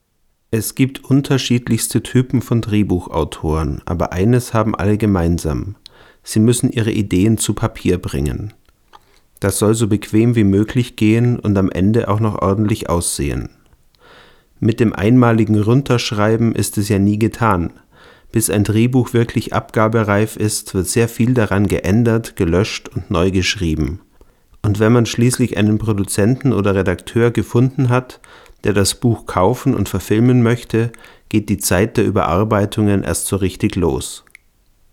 Sprachaufnahmen - Bitte um Beurteilung
Alle wurden hier in meinem Wohnzimmer (Dachwohnung mit Teppichboden) aus ca. 20 cm Abstand gemacht und anschließend in Audacity normalisiert; weitere Bearbeitung steckt noch nicht drin.
Ich habe jetzt zur guten Nacht noch ein wenig mit Software-Kompression herumgespielt: